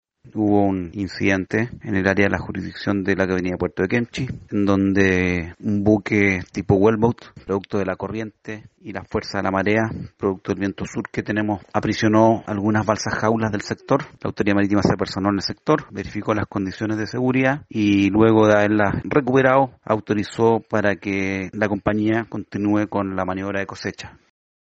Javier Mardones, Gobernador Marítimo de Castro, explicó cómo se habría producido el incidente que causó el escape de los salmones.